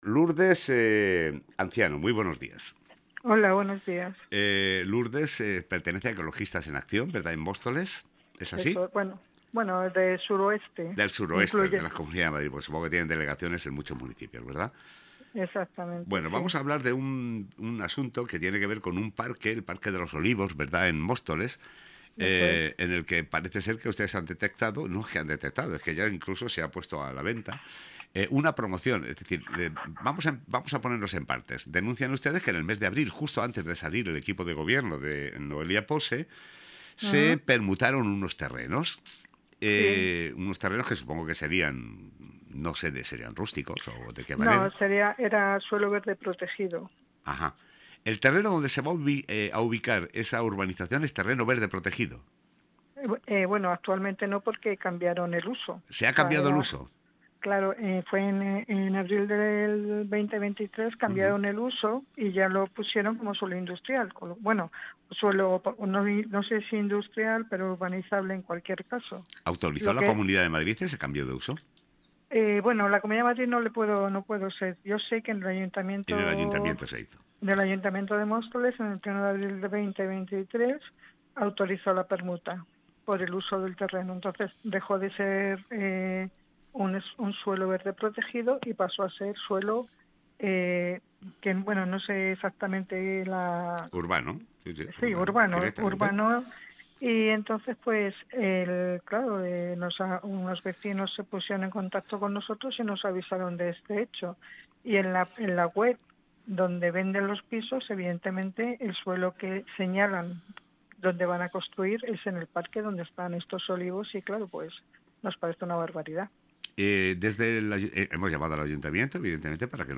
Entrevista completa: